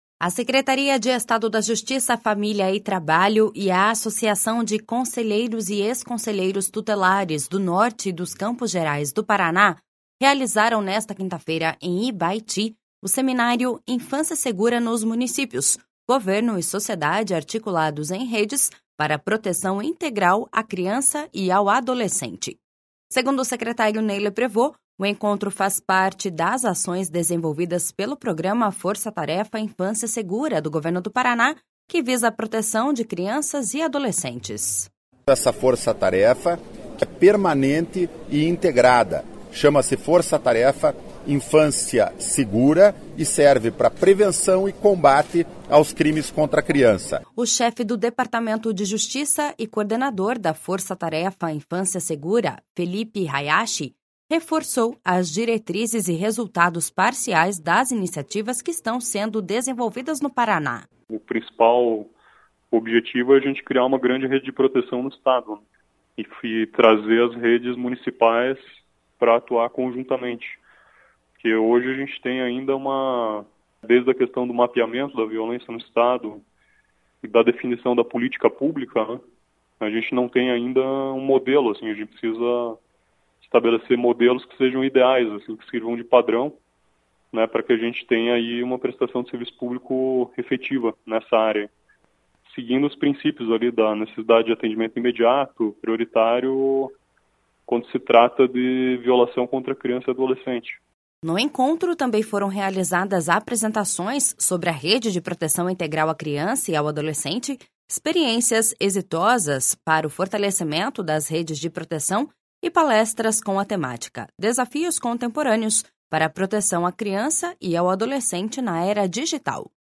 Segundo o secretário Ney Leprevost, o encontro faz parte das ações desenvolvidas pelo programa Força-Tarefa Infância Segura, do Governo do Paraná, que visa a proteção de crianças e adolescentes.// SONORA NEY LEPREVOST.//
O chefe do Departamento de Justiça e coordenador da Força-Tarefa Infância Segura, Felipe Hayashi, reforçou as diretrizes e resultados parciais das iniciativas que estão sendo desenvolvidas no Paraná.// SONORA FELIPE HAYASHI.//